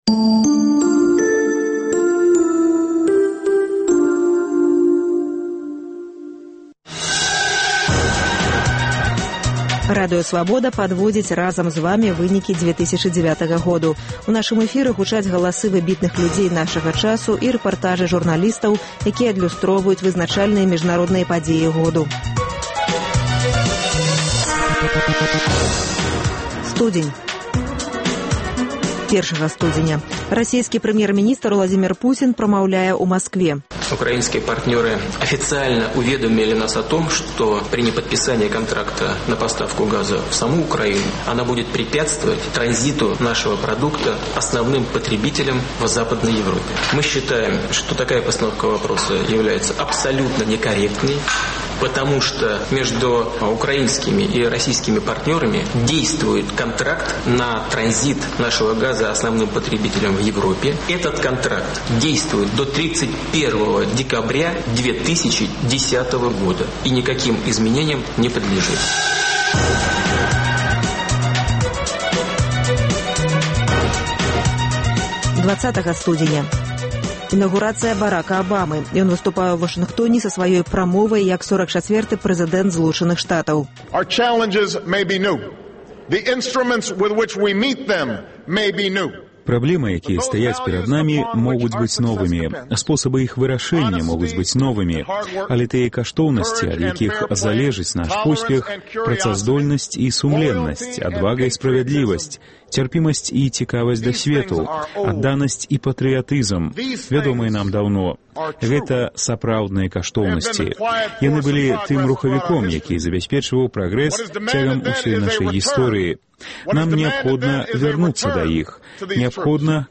Вынікі 2009-га ў галасох выдатных людзей нашага часу і рэпартажах, у якіх адлюстраваліся найважнейшыя падзеі.